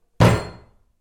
1_Udar po stoly tracetcya posuda.ogg